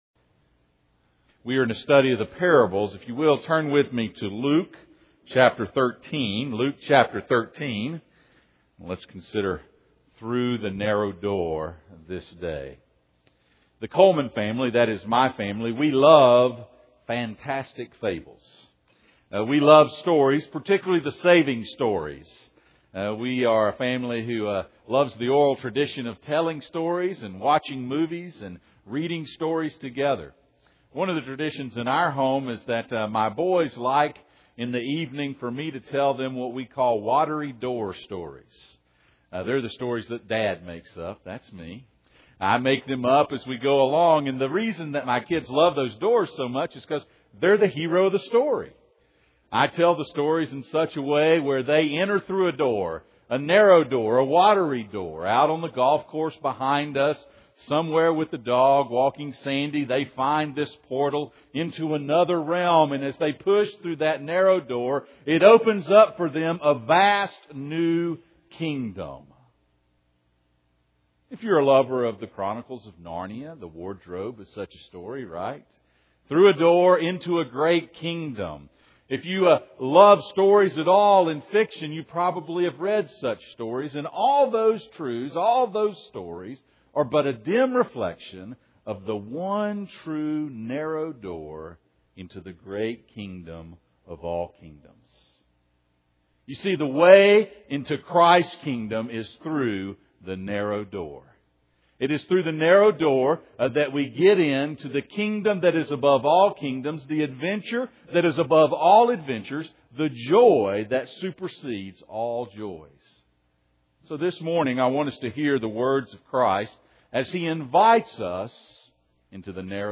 Luke 13:22-30 Service Type: Sunday Morning « The Rich Fool